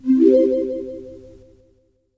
feat: add notification urgency-based sound system to swaync;